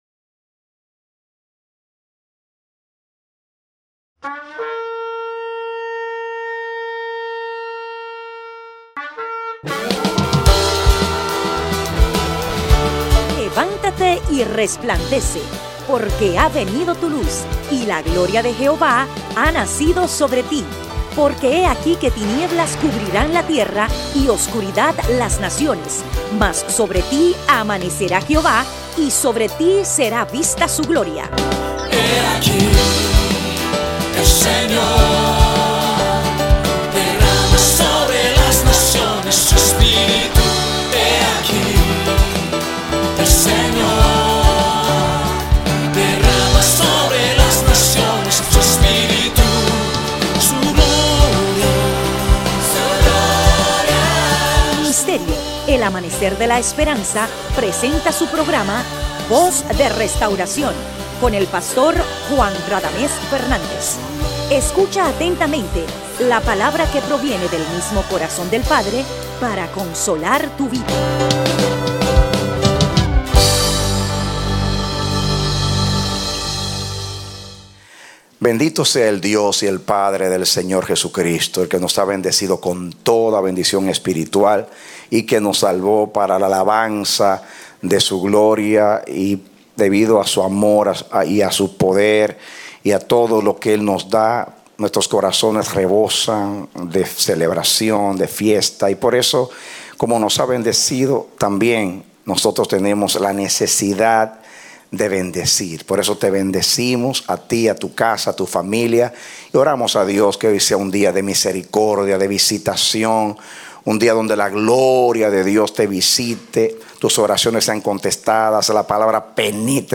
A mensaje from the serie "Mensajes." Predicado Octubre 16, 2014